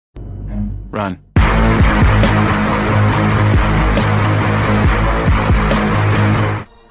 Run Meme Meme Effect sound effects free download